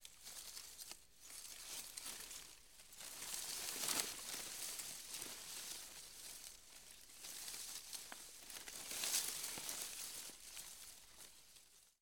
Leaves Rustle, Heavy; Leaves Rustling And Movement. - Rustling Leaves